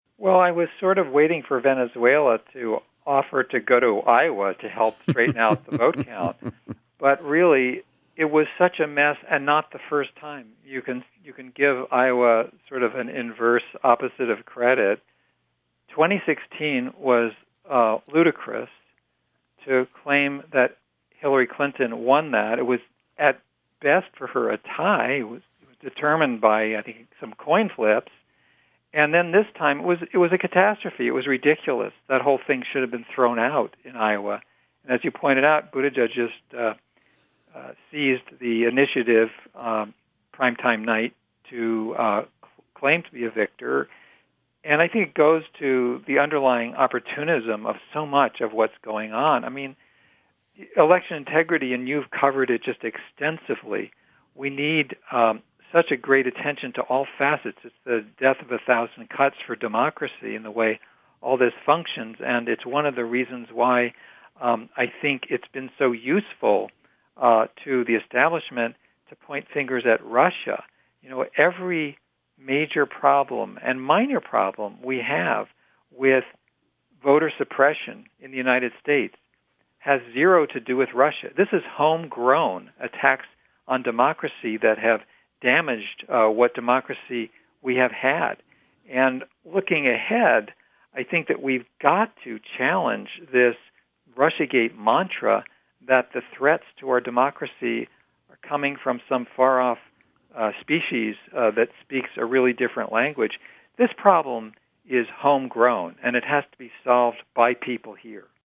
In Depth Interview: Progressive Activist Norman Solomon Offers Critical Comments on Sanders and Biden Campaigns